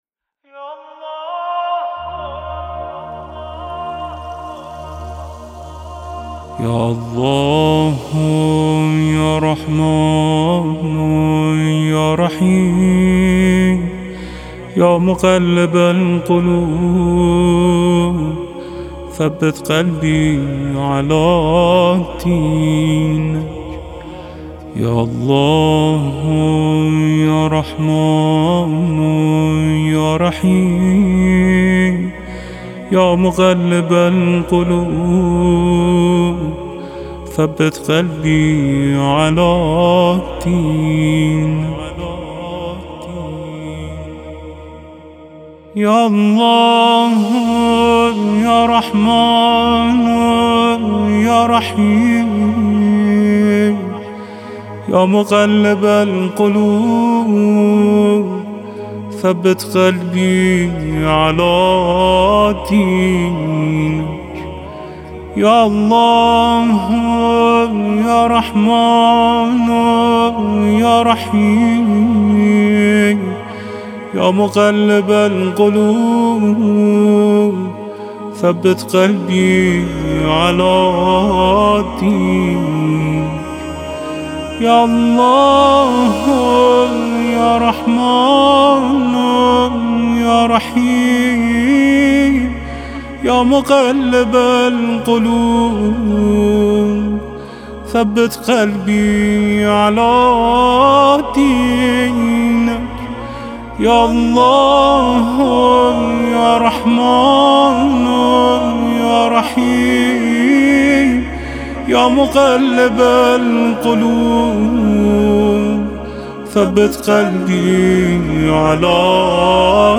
نوای دلنشین